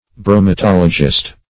Bromatologist \Bro`ma*tol"o*gist\, n. One versed in the science of foods.